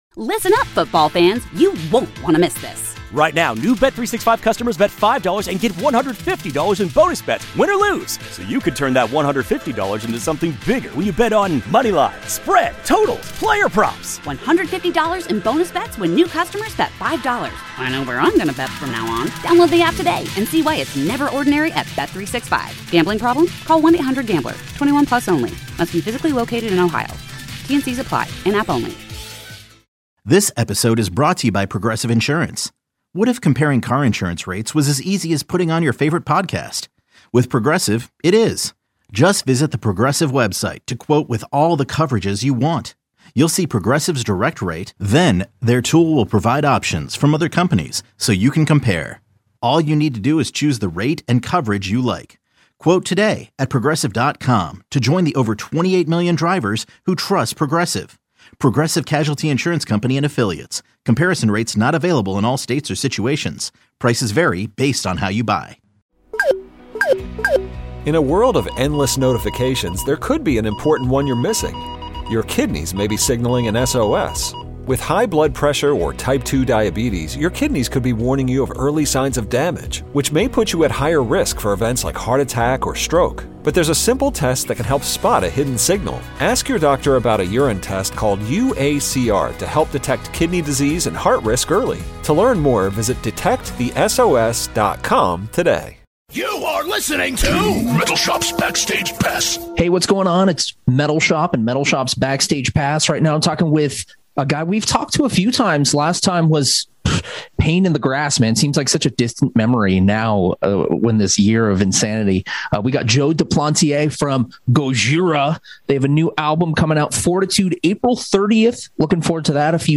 Gojira is gearing up to release "Fortitude" on April 30th, so I caught up with vocalist Joe Duplantier. We chatted about staying home with the kids, working on an album during a pandemic, designing his own metal t-shirts and much more.